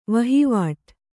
♪ vahivāṭ